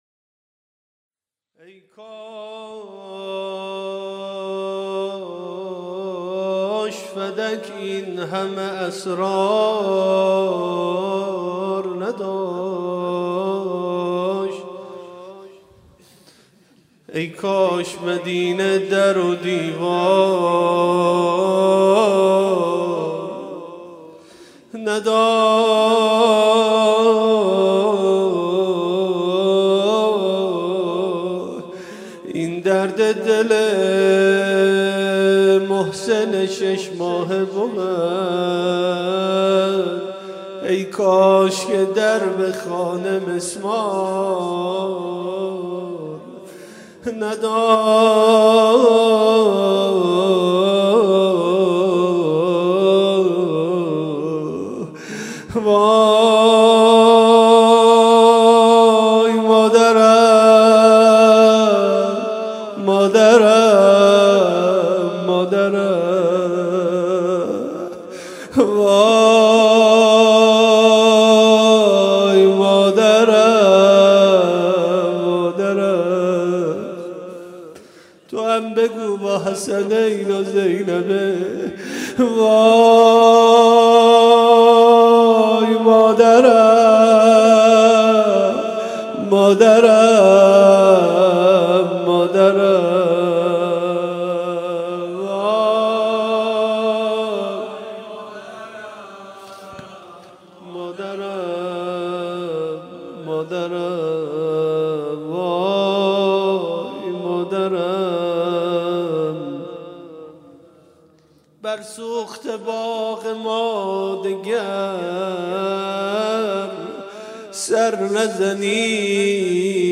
8 بهمن 96 - هیئت شبان القاسم - روضه